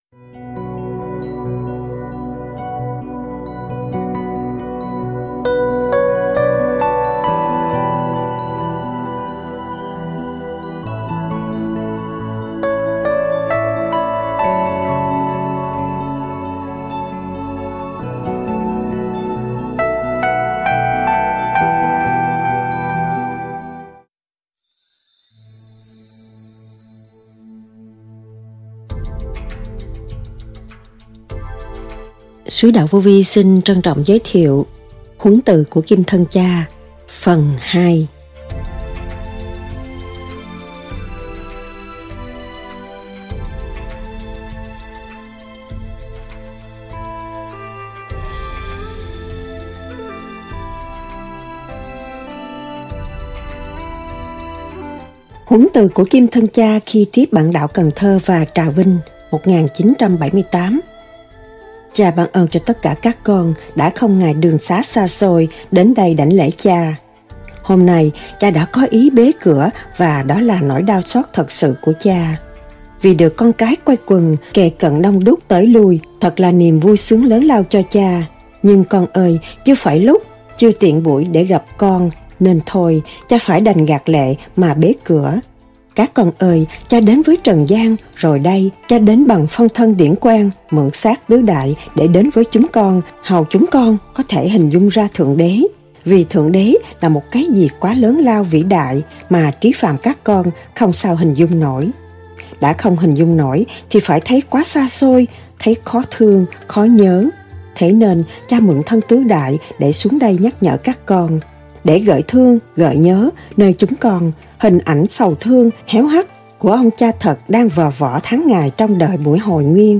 SÁCH NÓI , THUYẾT GIẢNG